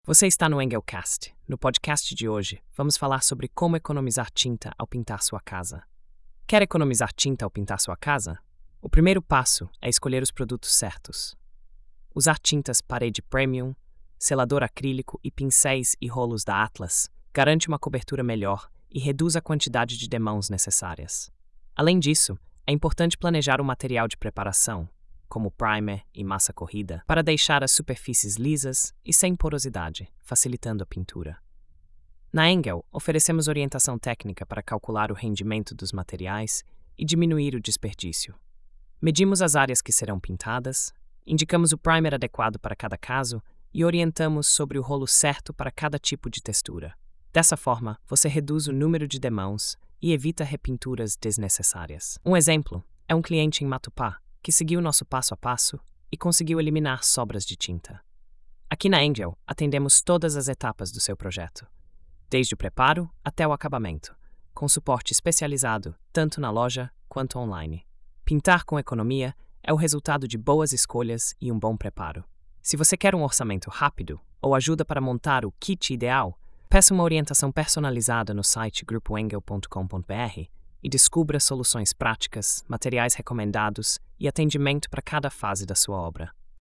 Narração automática por IA • Construção & Reformas